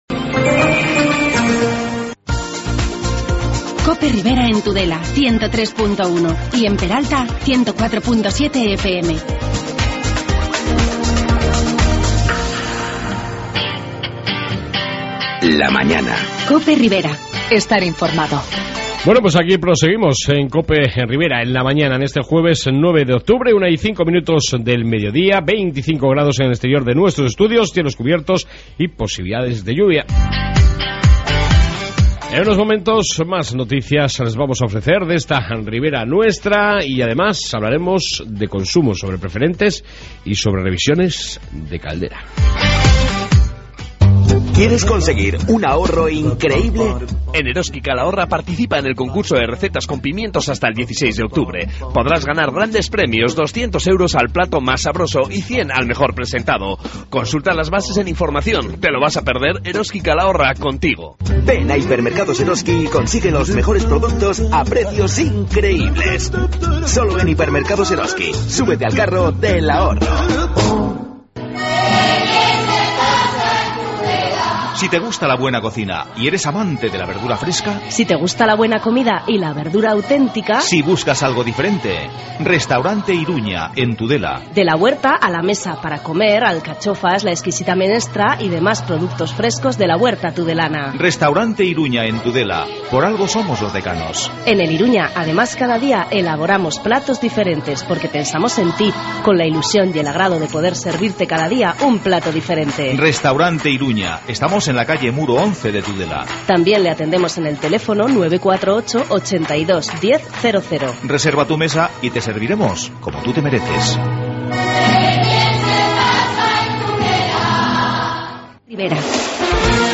AUDIO: Seguimos con el Informativo y entrevista sobre consumo...